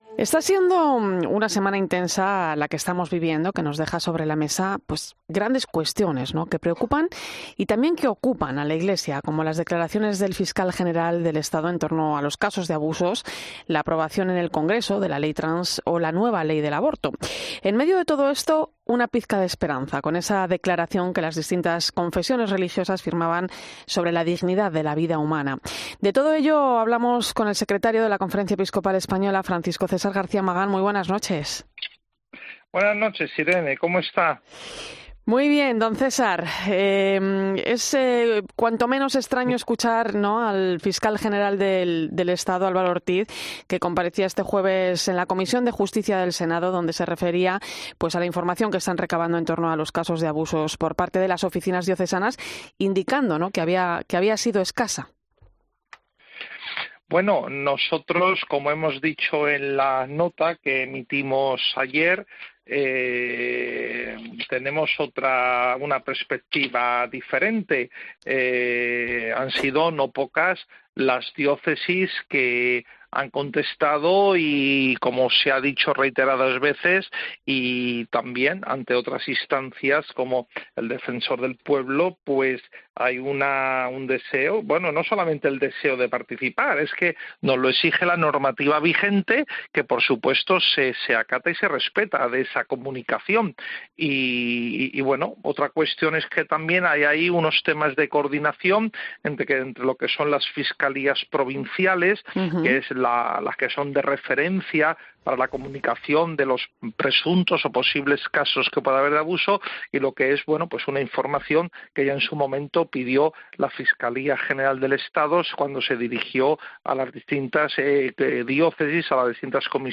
Está siendo una semana intensa la que estamos viviendo y que nos deja sobre la mesa grandes cuestiones que preocupan y también ocupan a la Iglesia, como las declaraciones del fiscal general del Estado en torno a los casos de abusos , la aprobación en el Congreso de la ley trans o la nueva ley del aborto … En medio de todo esto, una pizca de esperanza con la declaración que las distintas confesiones religiosas firmaban sobre la dignidad de la vida humana . De todo ello hemos hablado en 'La Linterna de la Iglesia' con monseñor Francisco César García Magán , secretario general de la Conferencia Episcopal Española.